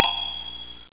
ting.au